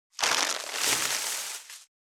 647コンビニ袋,ゴミ袋,スーパーの袋,袋,買い出しの音,ゴミ出しの音,袋を運ぶ音,
効果音